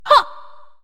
One of Princess Daisy's voice clips in Mario Party 6